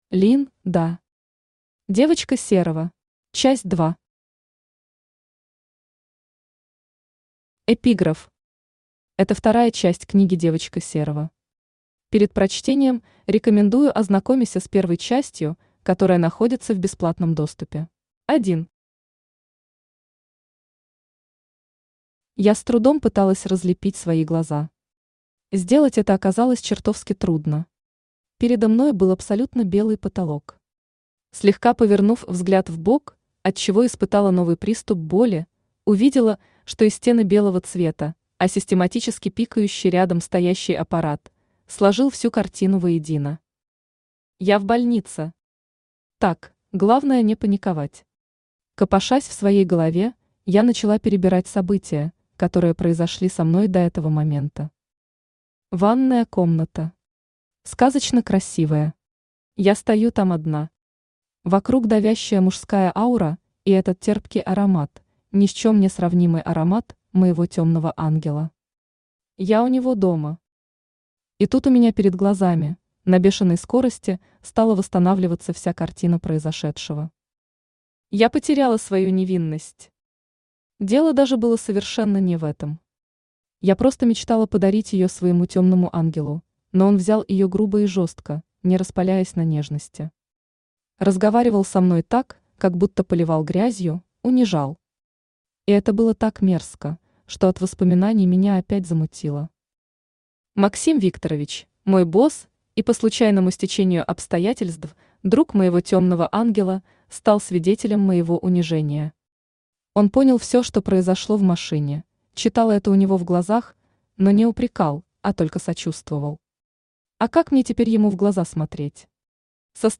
Аудиокнига Девочка Серого. Часть 2 | Библиотека аудиокниг
Часть 2 Автор Лин Да Читает аудиокнигу Авточтец ЛитРес.